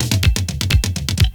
DRUMFILL10-L.wav